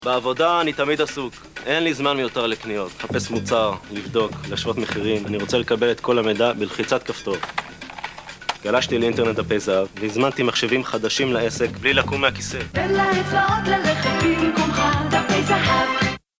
English and Hebrew speaker, Baritone, Narration, Commercials, Character work, NYC voice overs
Sprechprobe: Industrie (Muttersprache):